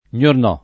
gnornò [ + n’orn 0+ ] → signornò